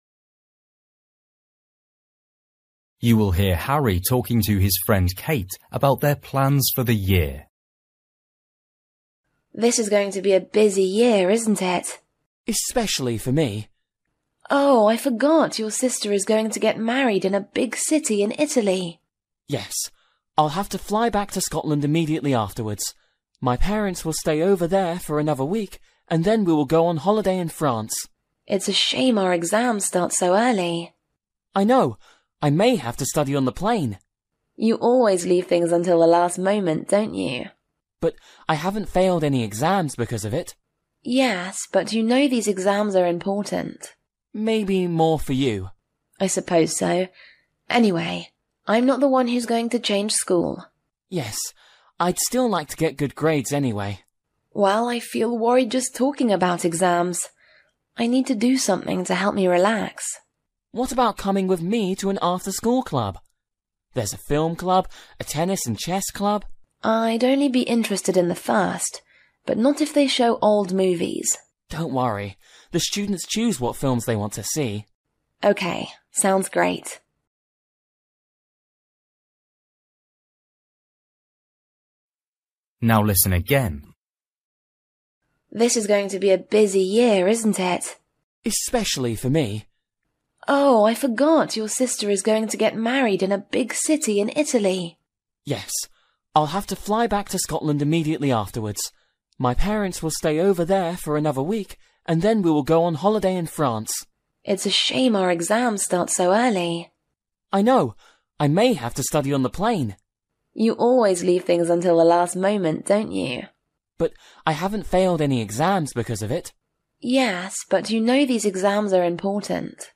Bài tập trắc nghiệm luyện nghe tiếng Anh trình độ sơ trung cấp – Nghe một cuộc trò chuyện dài phần 21